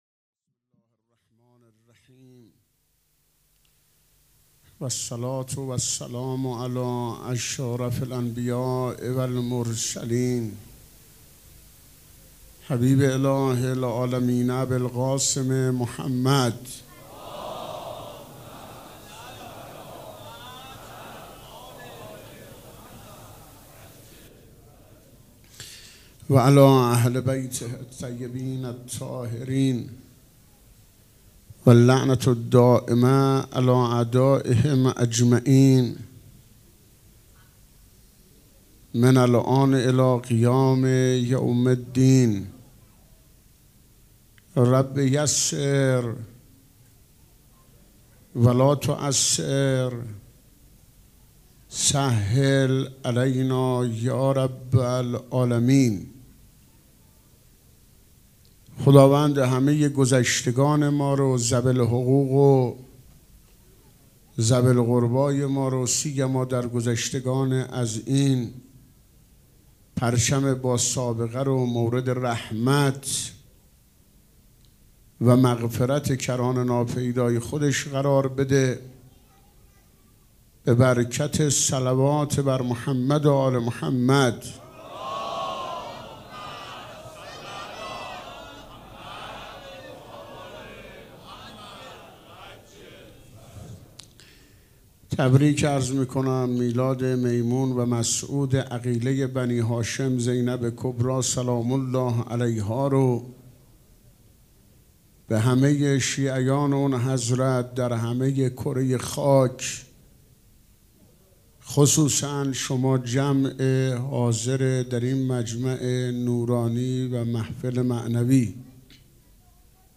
سخنرانی
مراسم جشن ولادت حضرت زینب (سلام الله علیها)